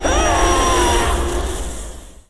Cry2.wav